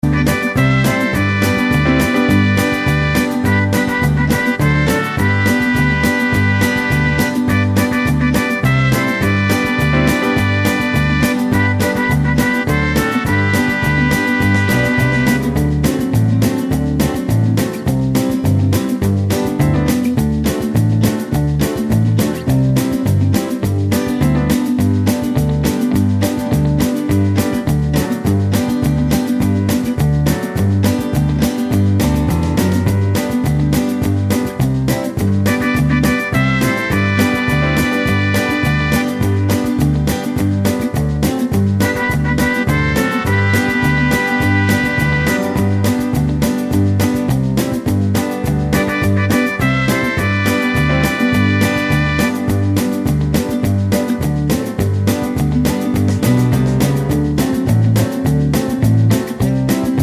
no Backing Vocals Country (Male) 2:42 Buy £1.50